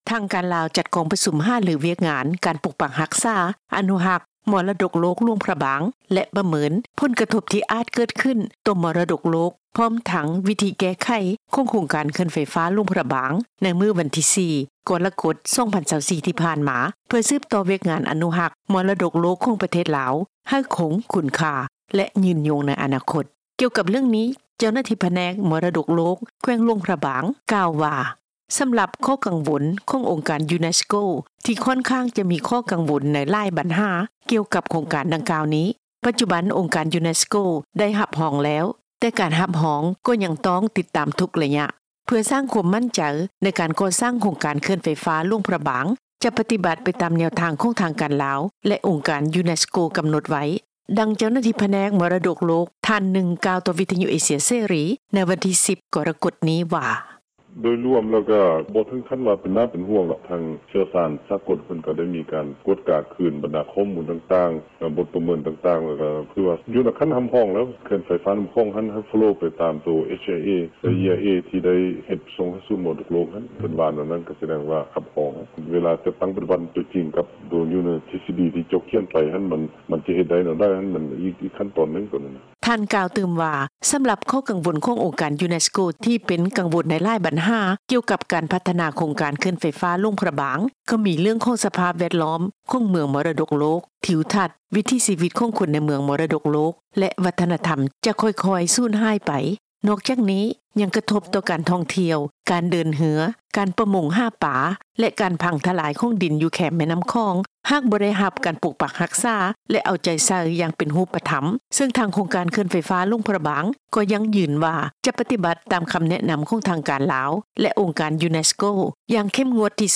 ດັ່ງ ເຈົ້າໜ້າທີ່ ກົມມໍລະດົກໂລກ ທ່ານໜຶ່ງ ກ່າວຕໍ່ ວິທຍຸ ເອເຊັຽເສຣີ ໃນມື້ດຽວກັນນີ້ວ່າ:
ດັ່ງ ປະຊາຊົນລາວທ່ານໜຶ່ງ ກ່າວຕໍ່ ວິທຍຸ ເອເຊັຽເສຣີ ໃນມື້ດຽວກັນນີ້ວ່າ: